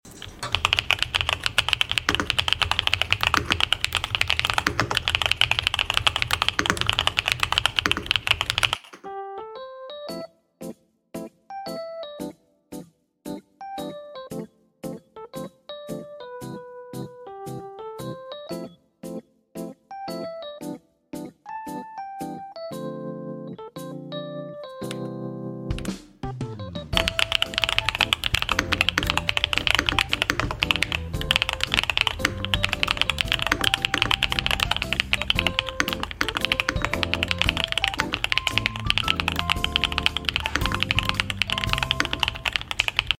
🌸YUNZII B75 Pro pink keyboard sound effects free download